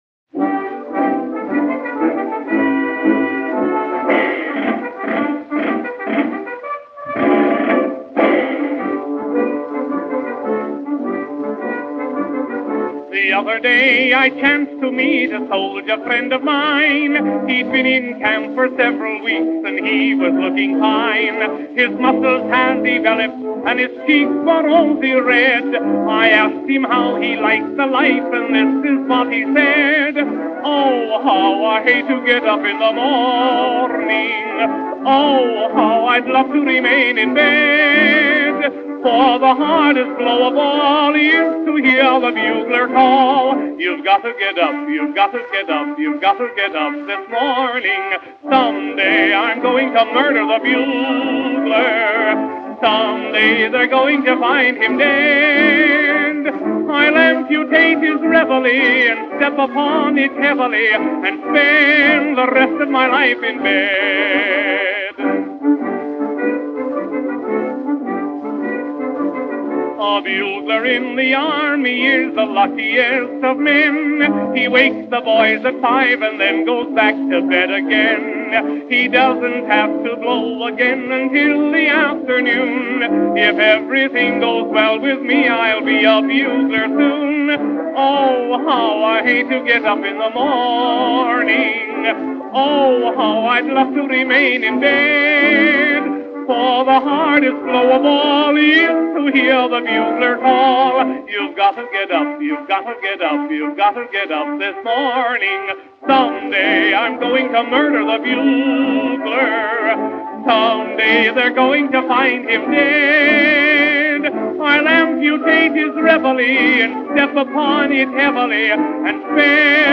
There were also comic songs